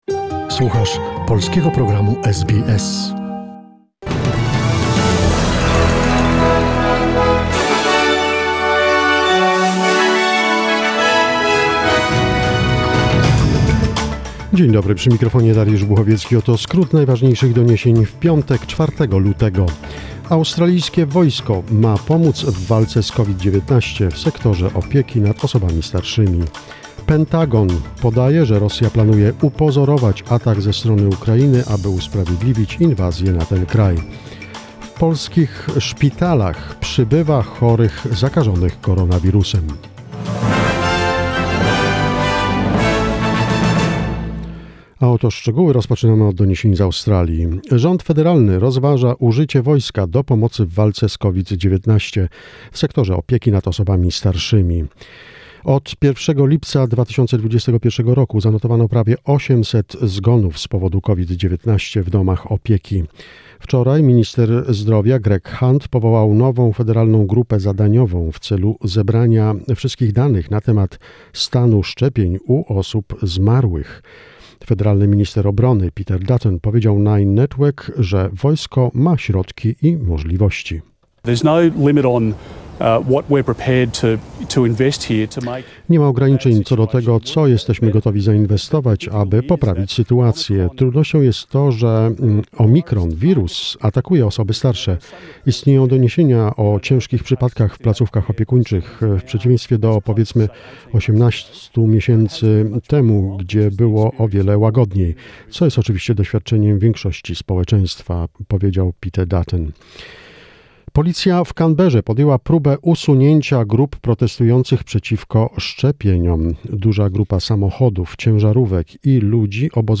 SBS News in Polish, 4 February 2021